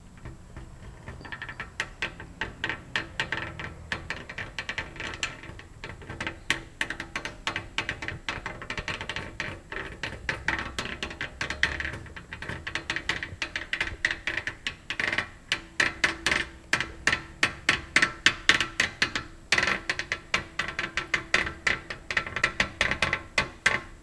Venetian Blinds Closing, Creaks